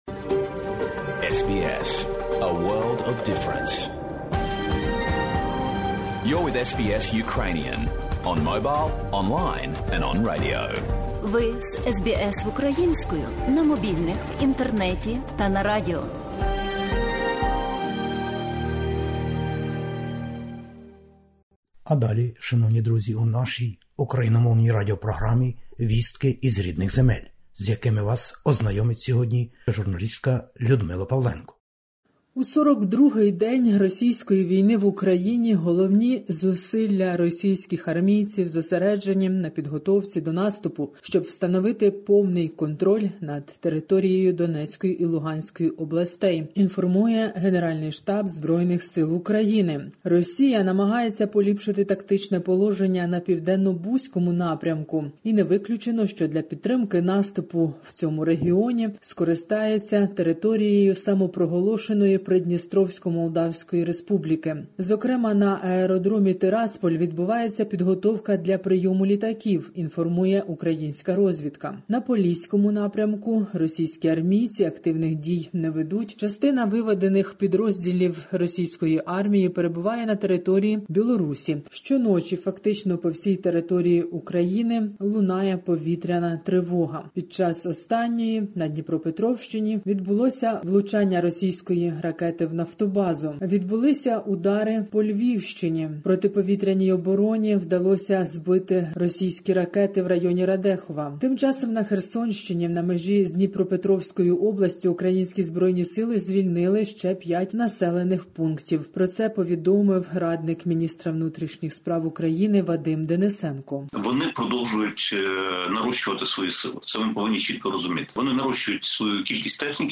Добірка новин із воюючої України. Звернення Президента України. Нічні тривоги про небезпеку по всій Україні.